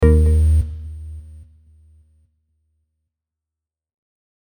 Buttons and Beeps
Error 2.mp3